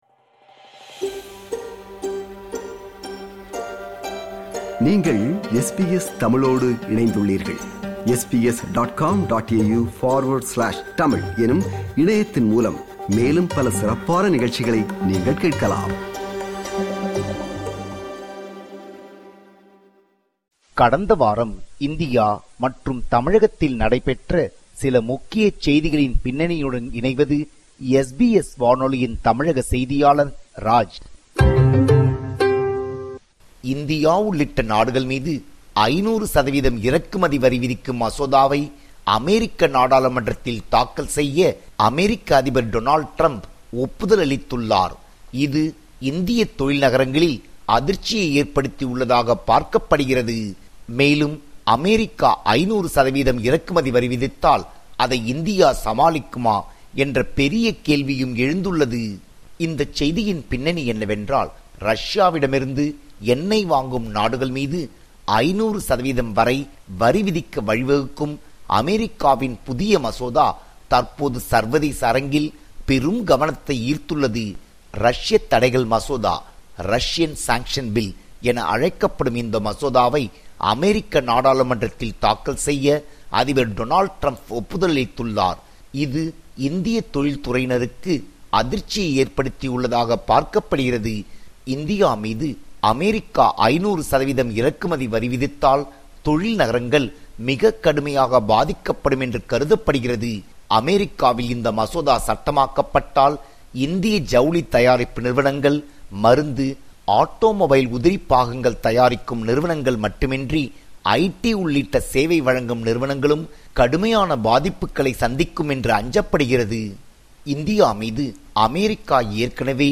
இந்தியா & தமிழ்நாடு: கடந்தவார செய்திகளின் தொகுப்பு